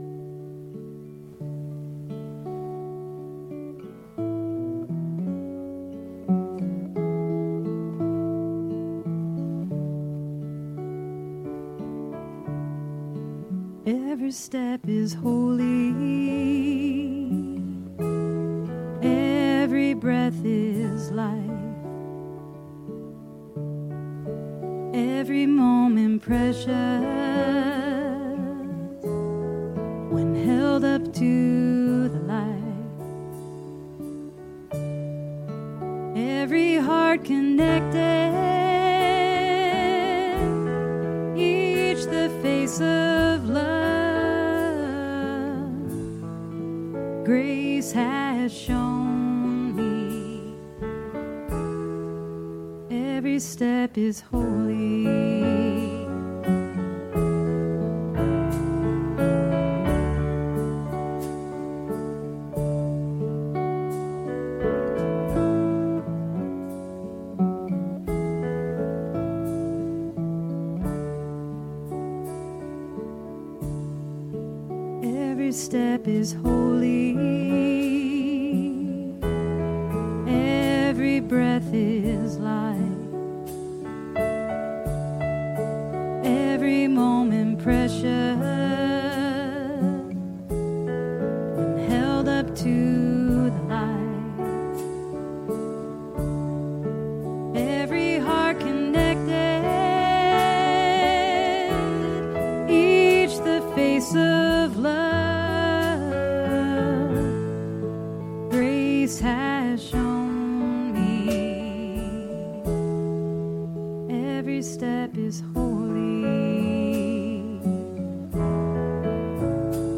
Grateful for the Journey – Celebration Service
Song-Every-Step-is-Holy.mp3